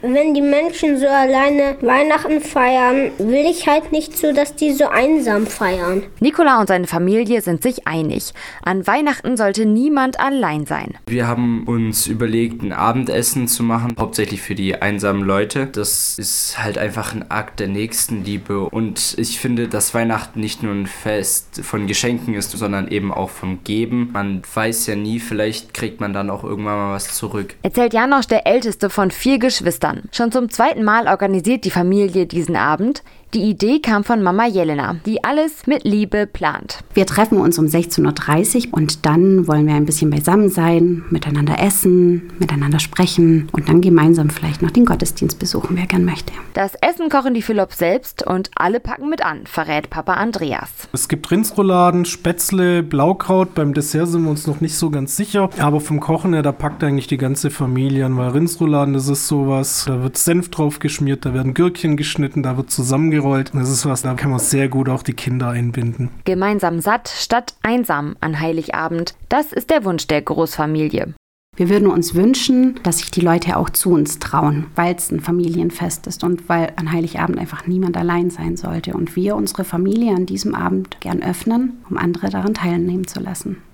Radioreportage stellt die bemerkenswerte Aktion vor.